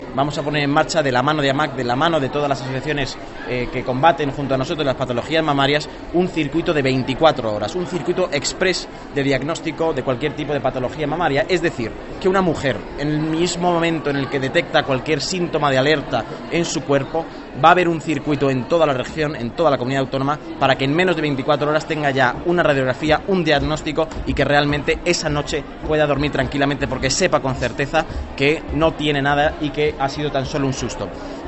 El portavoz del Gobierno regional, Nacho Hernando: